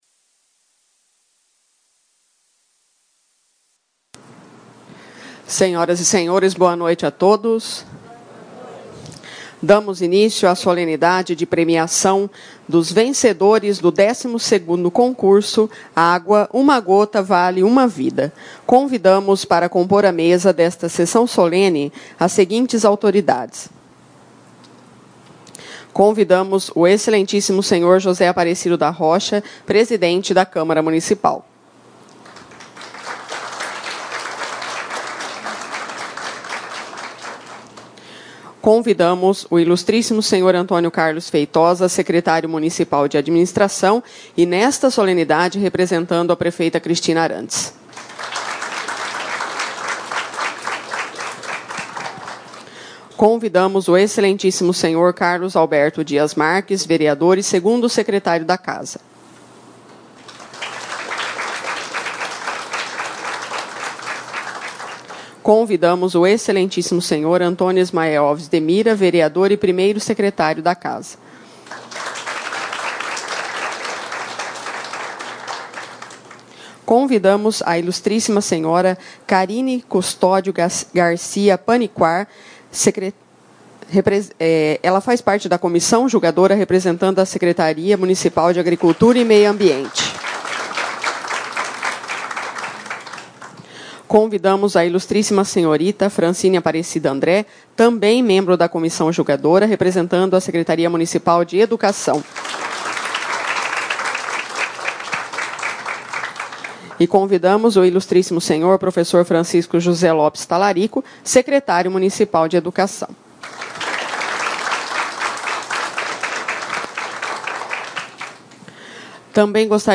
Sessões Solenes/Especiais